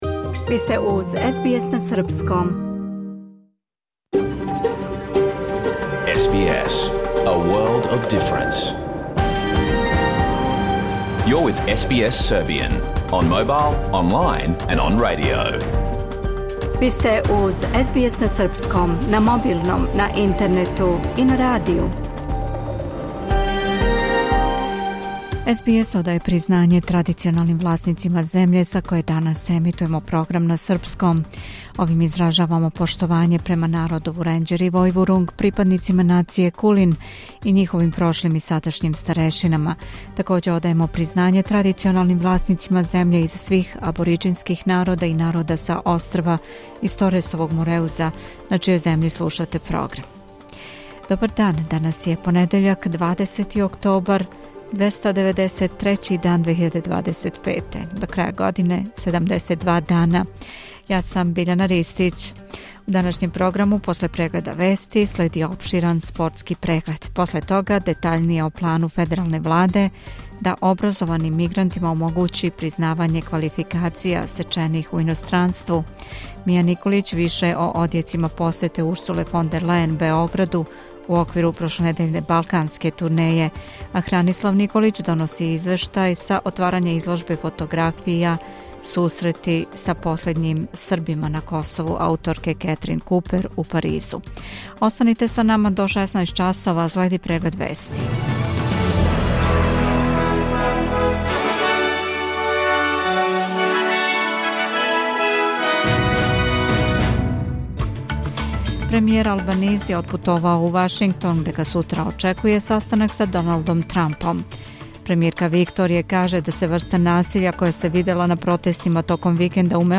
Програм емитован уживо 20. октобра 2025. године